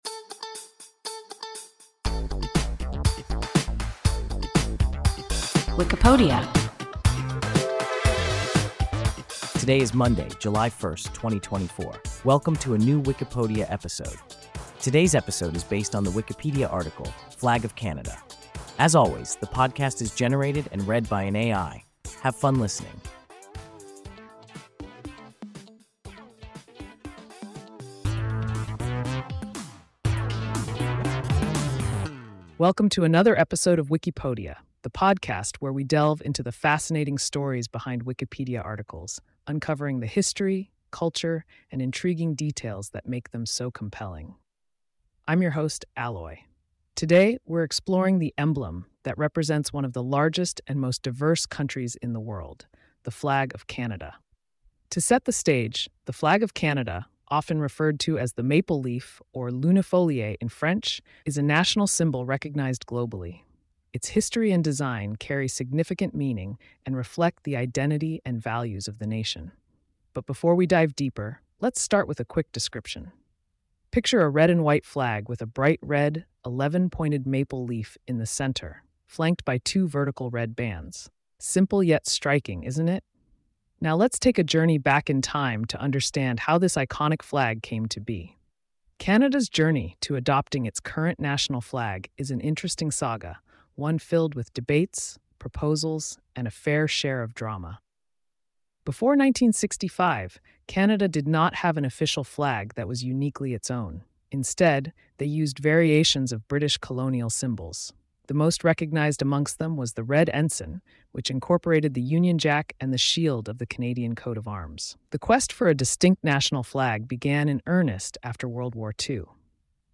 Flag of Canada – WIKIPODIA – ein KI Podcast